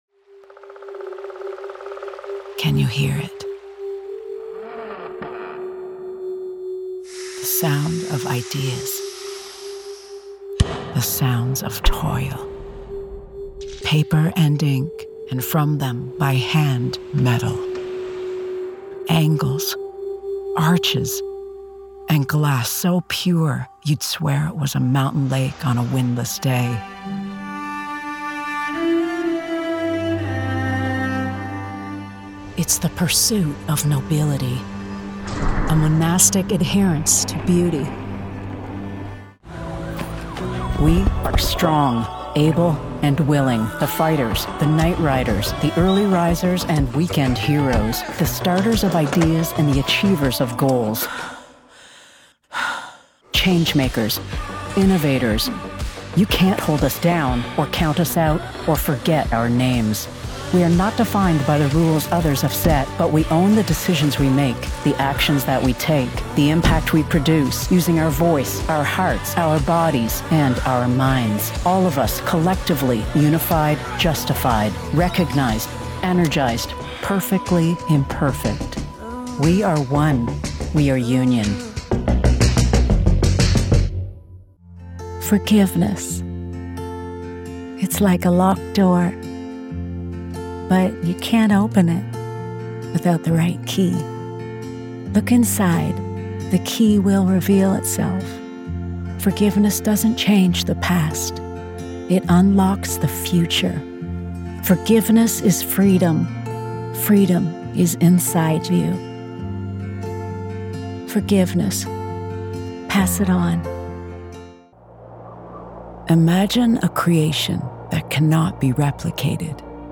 Female Voice Over, Dan Wachs Talent Agency.
Warm, sassy, gritty, real, mature.